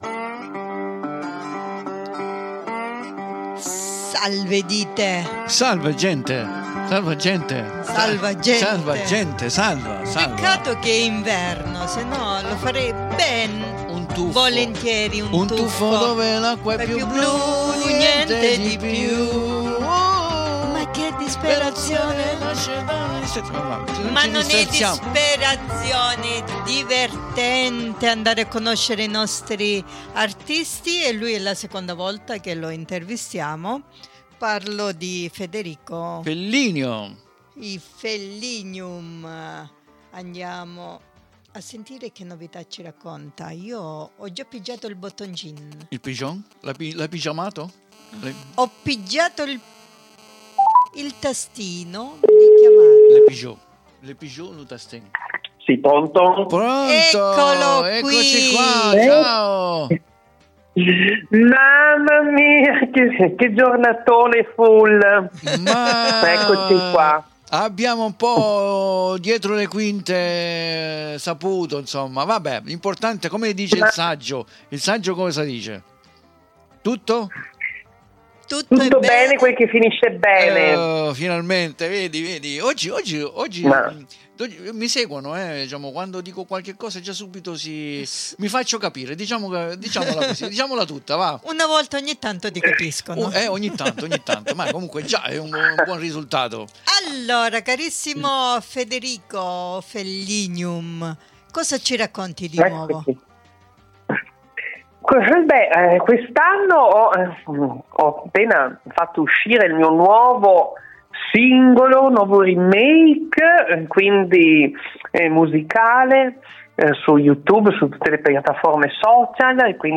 CAPIRETE DI PIÚ ASCOLTANDO QUESTA CHIACCHERATA , CONDIVISA QUI IN DESCRIZIONE .